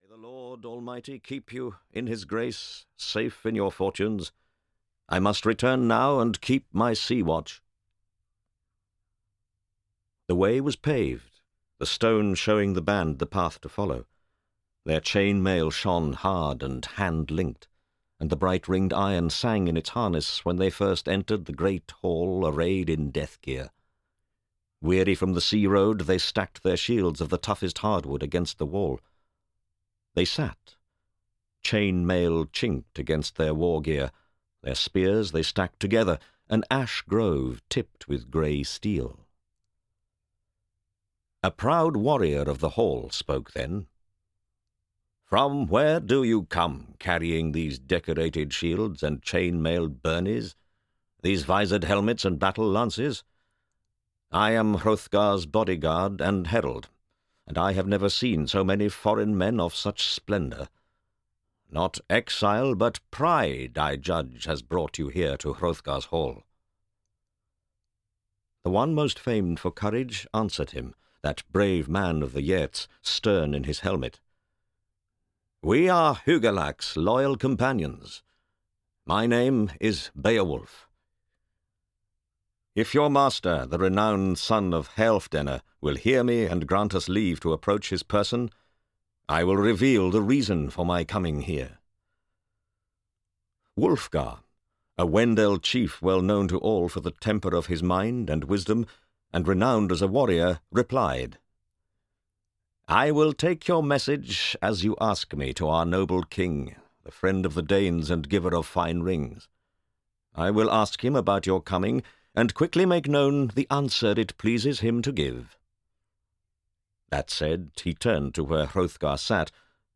Audiobook Beowulf.
Ukázka z knihy